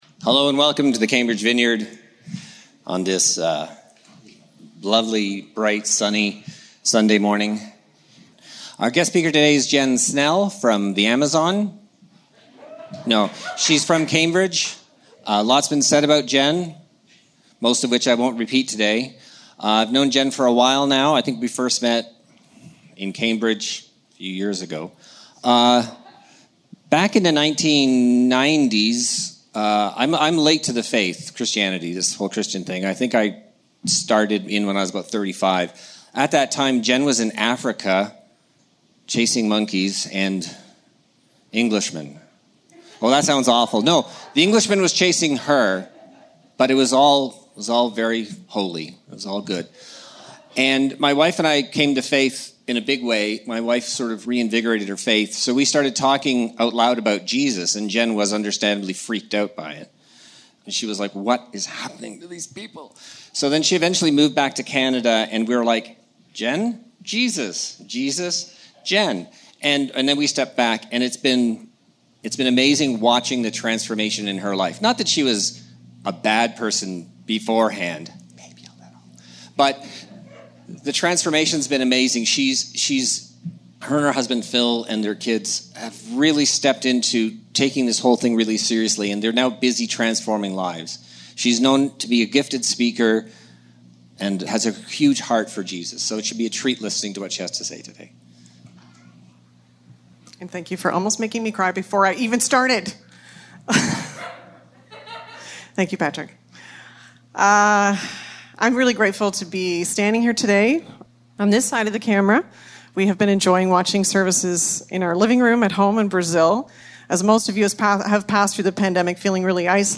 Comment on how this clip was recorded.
When Royalty Meets Humility Service Type: Sunday Morning 3rd week of Advent.